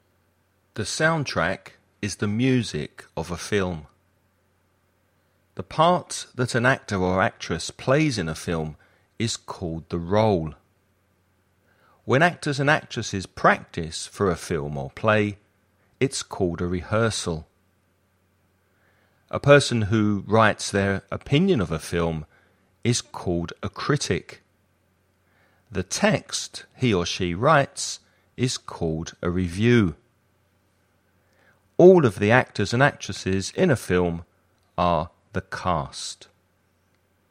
Listening Practice
You’re going to listen to a man talking about cockroaches.